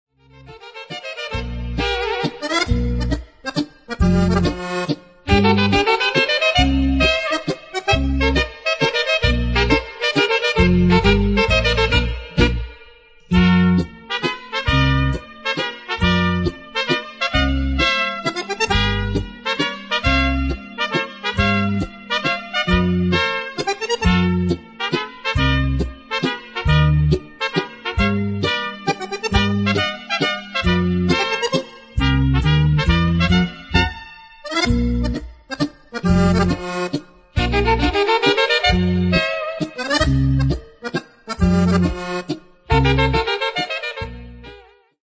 polca